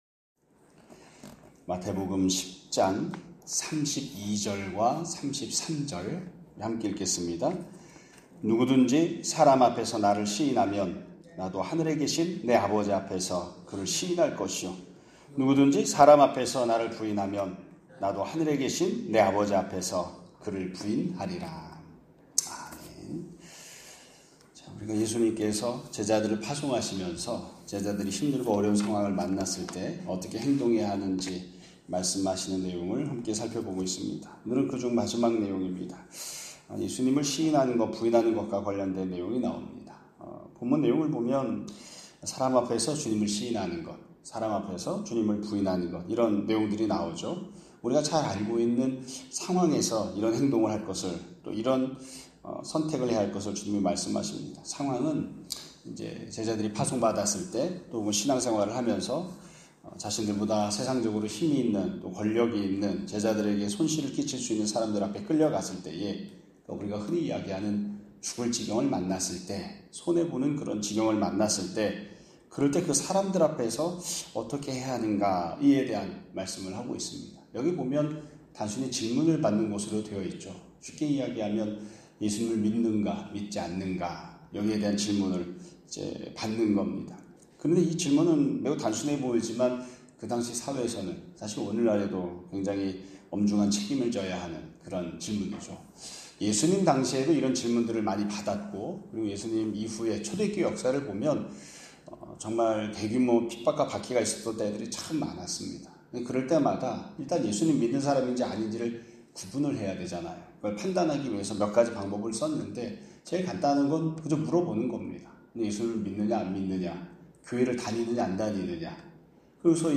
2025년 8월 14일 (목요일) <아침예배> 설교입니다.